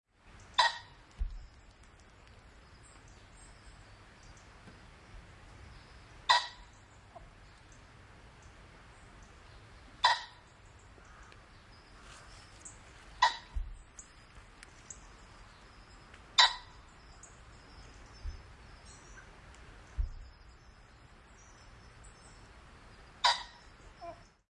描述：公鸡在苏格兰Eskdalemuir的Samye Ling的岗位上叫唤
Tag: 呱呱叫 野鸡